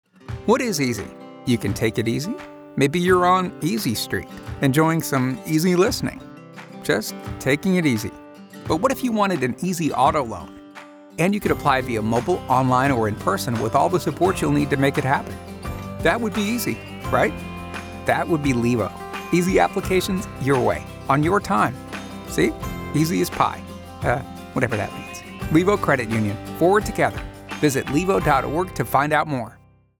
0:30 "The Easy Way" Commercial
Levo_Easy_Auto_Radio.wav